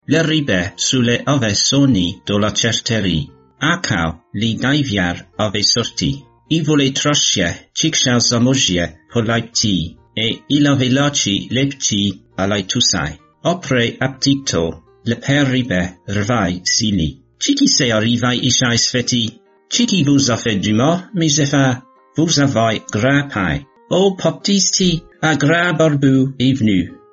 It sounds somewhat like a mixture of French and Italian, but pronounced by someone with an English accent.
18 October 2020 at 7:02 pm It sounds related to French but with some English influence in pronunciation.
23 October 2020 at 5:59 pm French-like but mostly incomprehensible, with a British accent?